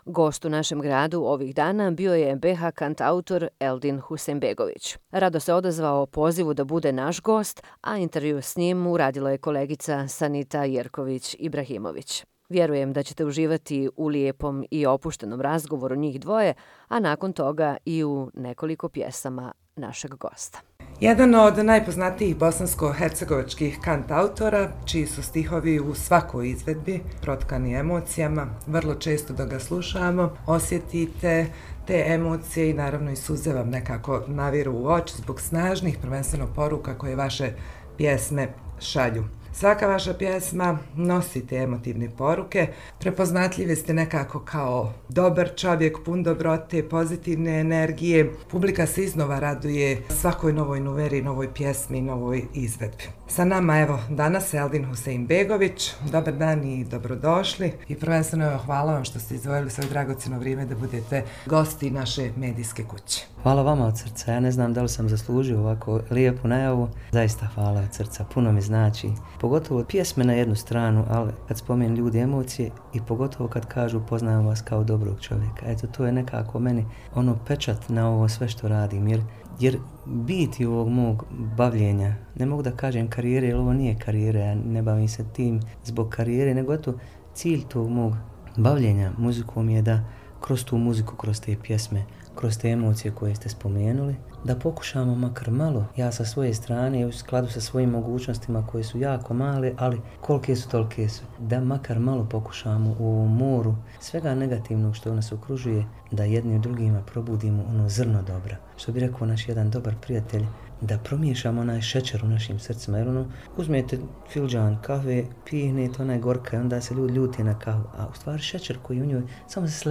Intervju sa kantautorom Eldinom Huseinbegovićem